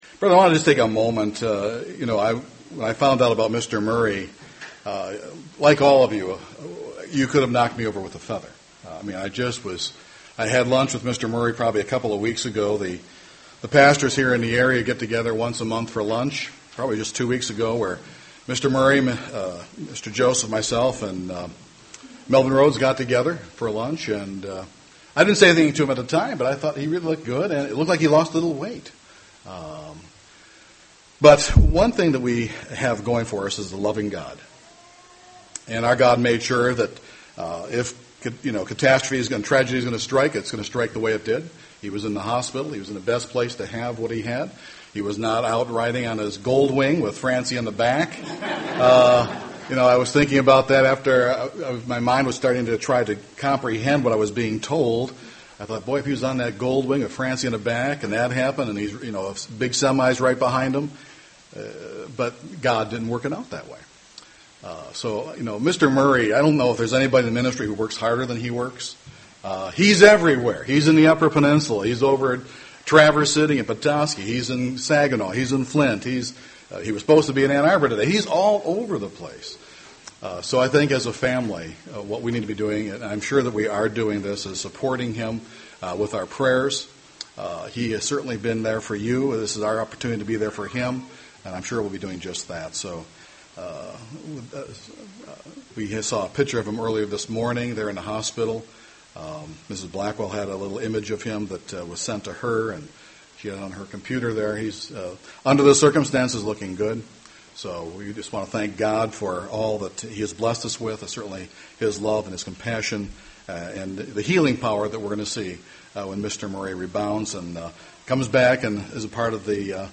On this Feast of Trumpets, let's look at the second coming of Jesus Christ from His perspective. It is different than ours.
UCG Sermon Transcript This transcript was generated by AI and may contain errors.